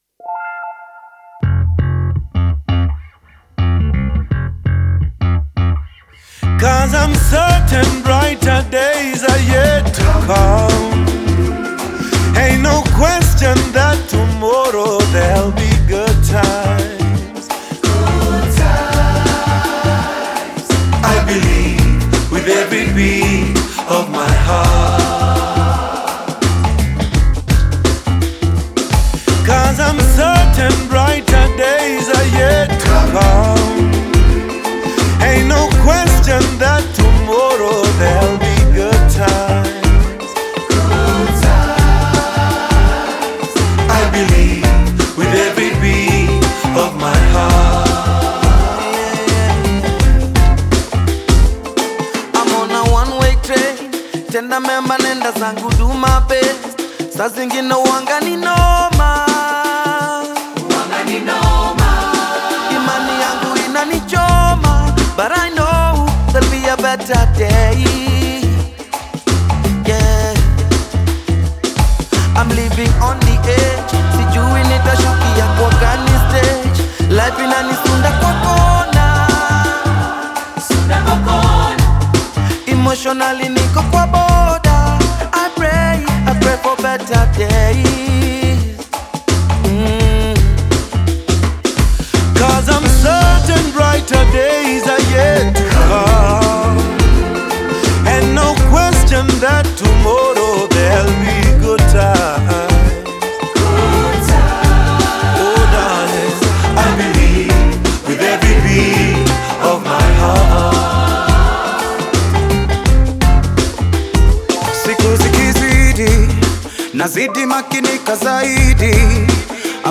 Kenyan Afro-Pop band